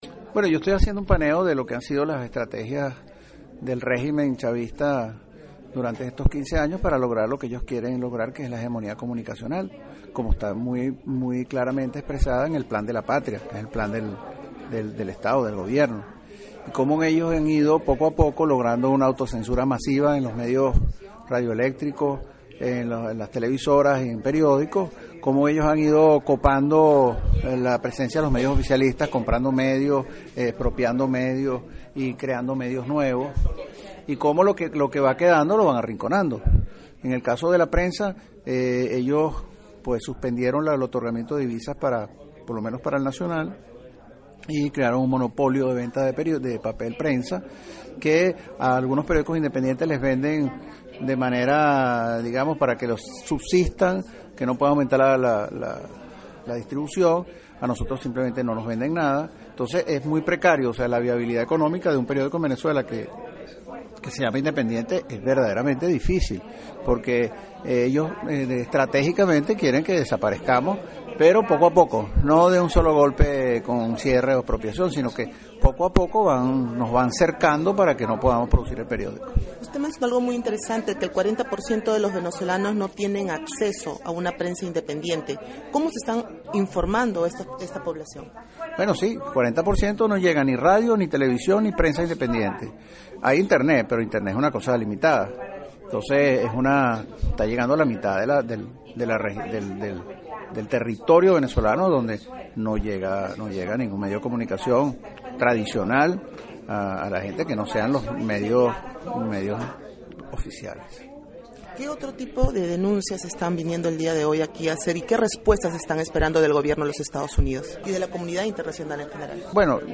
Entrevista Miguel Henrique Otero EL NACIONAL
En Washington, los propietarios de dos de los diarios más influyentes en Venezuela y Ecuador, unieron sus voces para denunciar los atropellos que sufre a diario la libertad de expresión en sus países. Entrevista de 6 minutos.